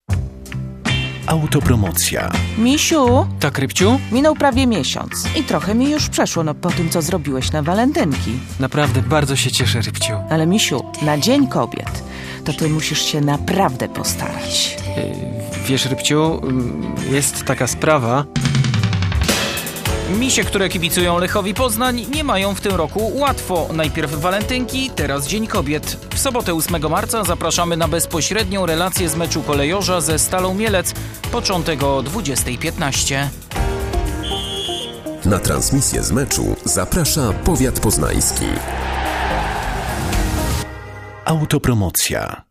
Radiowe zapowiedzi meczów